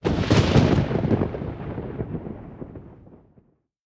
PixelPerfectionCE/assets/minecraft/sounds/ambient/weather/thunder2.ogg at mc116
thunder2.ogg